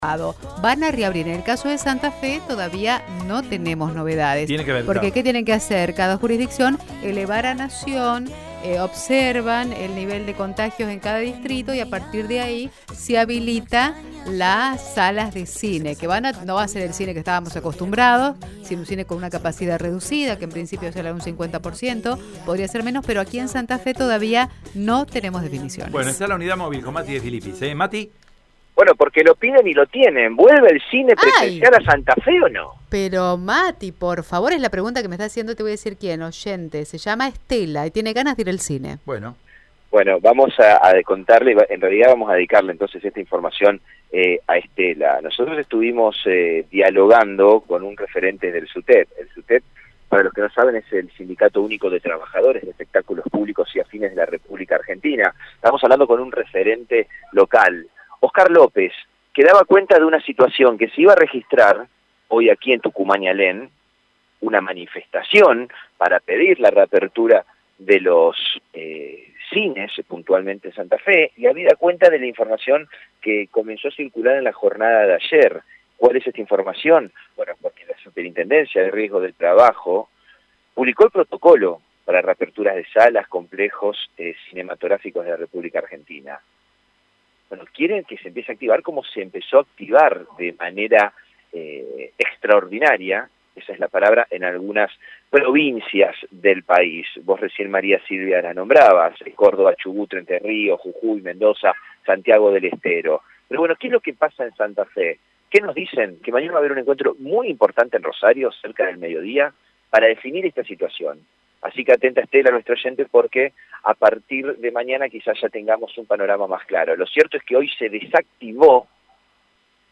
AUDIO DESTACADOProvinciales